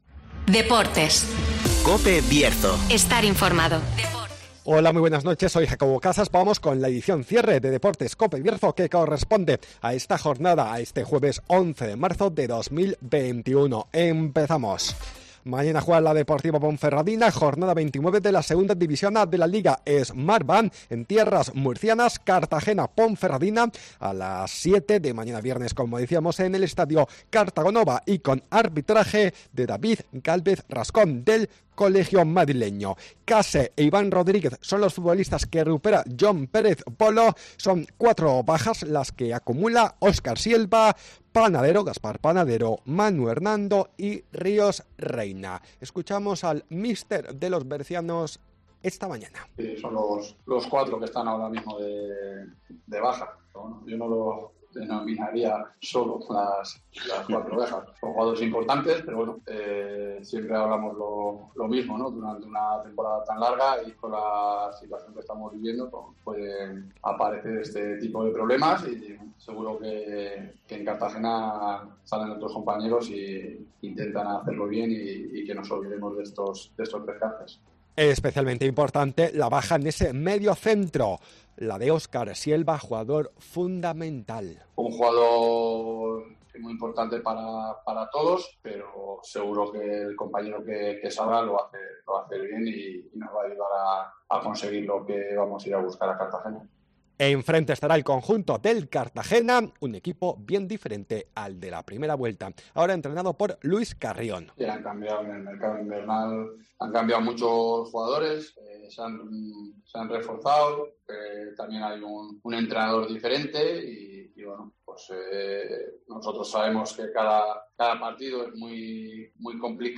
AUDIO: Previa del Cartagena - Ponferradina con declaraciones de Jon Pérez Bolo y Erik Morán